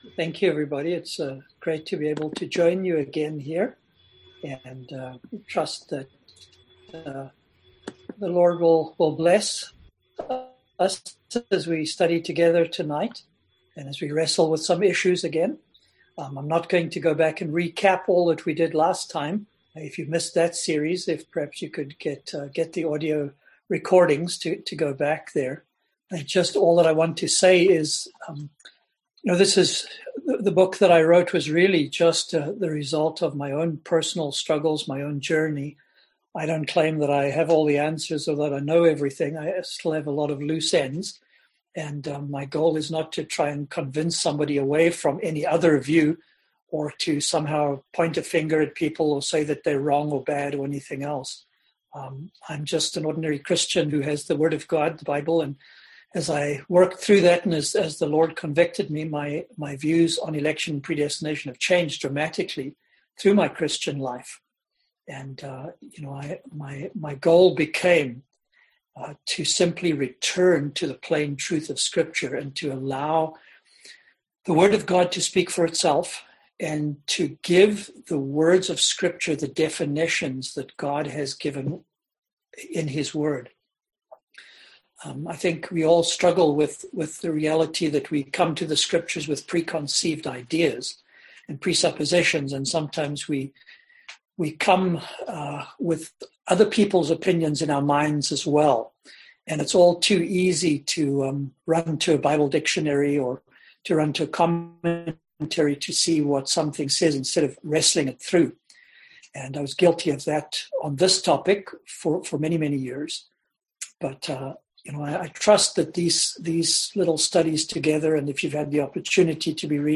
Service Type: Seminar Topics: Election , Predestination , The Church